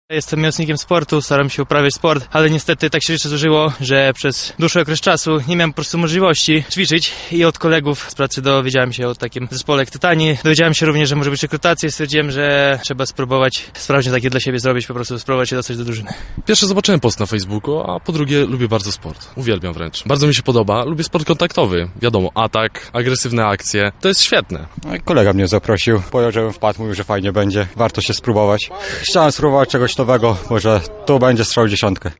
Dla wielu chętnych nabór do Tytanów to szansa na powrót do sportu czy spróbowanie czegoś nowego, o czym mówią obecni na rekrutacji.
Sonda.mp3